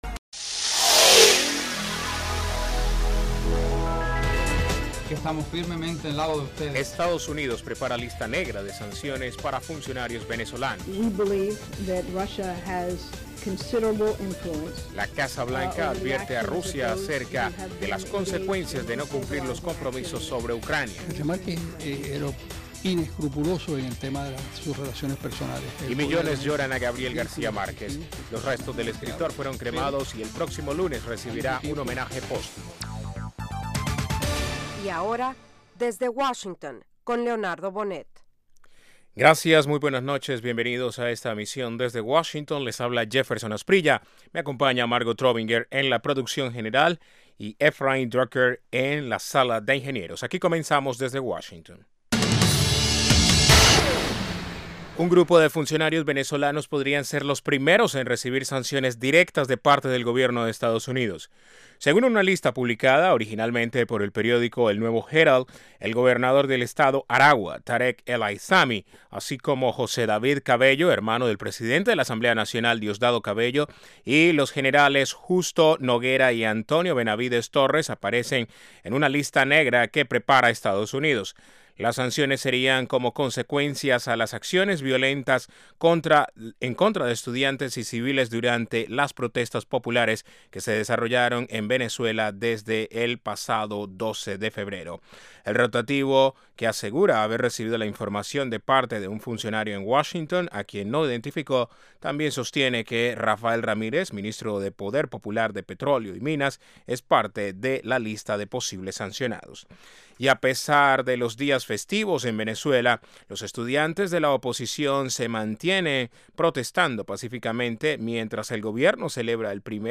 Diez minutos de noticias sobre los acontecimientos de Estados Unidos y el mundo.